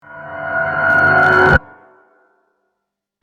Scary Riser Sound Button - Free Download & Play
Sound Effects Soundboard420 views